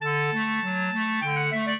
clarinet
minuet0-4.wav